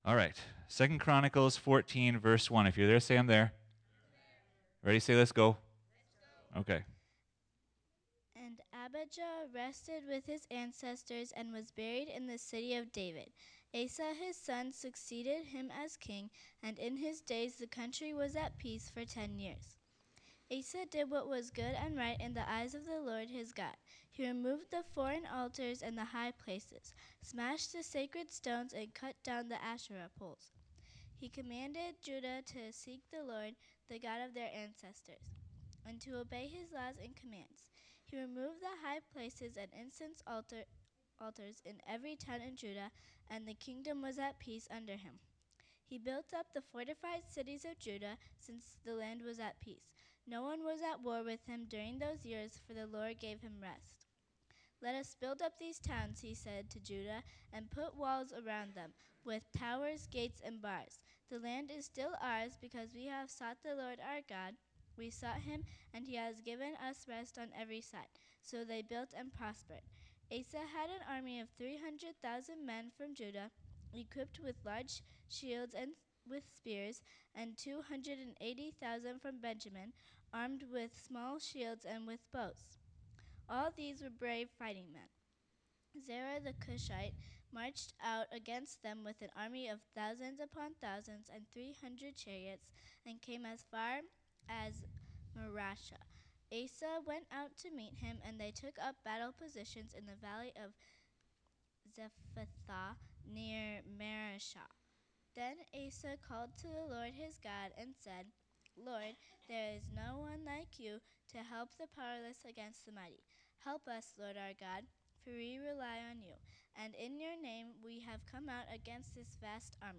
2025 Wholehearted Preacher